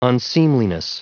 Prononciation du mot unseemliness en anglais (fichier audio)
Prononciation du mot : unseemliness